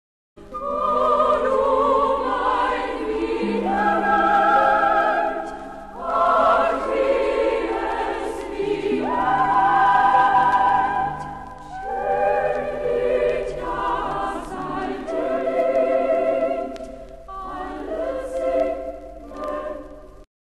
Konzertwalzer
für gemischten Chor und Orchester
Besetzung: gemischter Chor, Klavier (ggf. 4-händig)